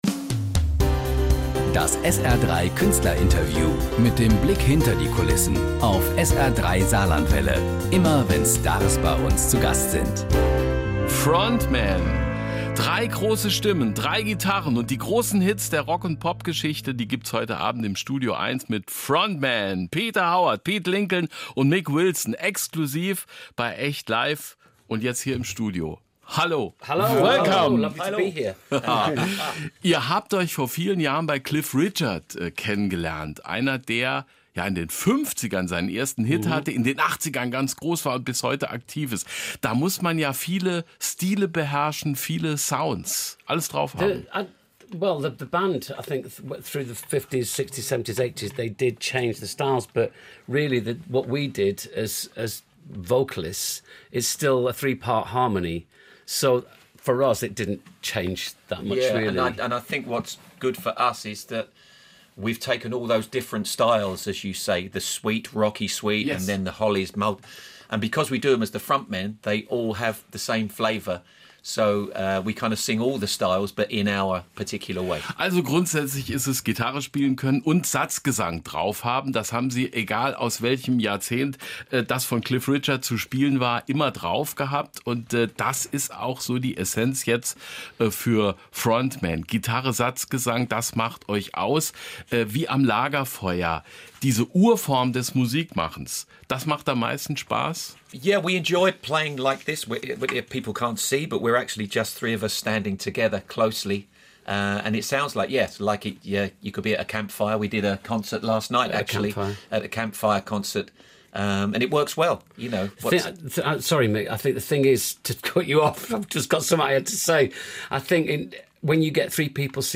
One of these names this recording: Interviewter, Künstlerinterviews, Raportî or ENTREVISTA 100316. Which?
Künstlerinterviews